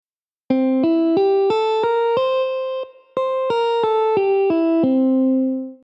1-3-5-6-b7
(C-E-G-A-Bb-C)